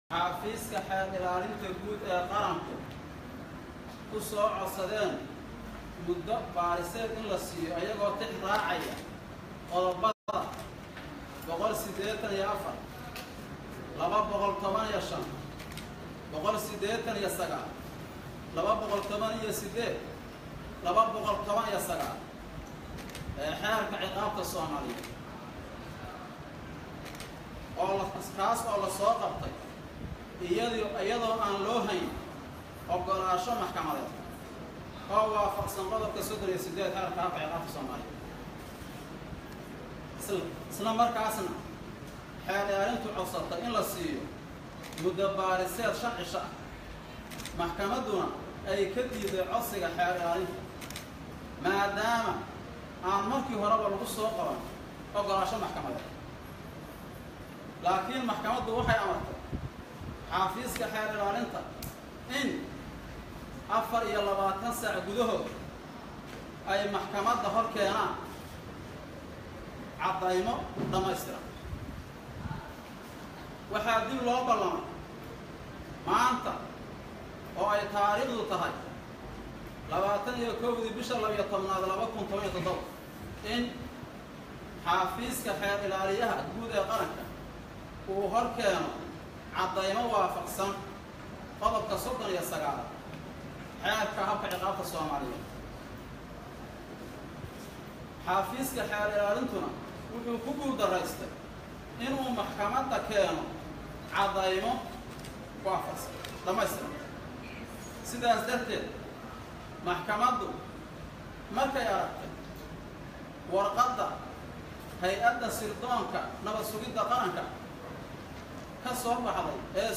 Intaas kaddib ayaa waxaa goobta is qabsaday sawaxan iyo sacab, dadkii goobta ku sugnaa ay mar qura isla heleen, waxaana loogu hambalyeynayay siyaasiga C/raxmaan C/shakuur xoriyadii ay dib ugu soo celisay Maxkamadda.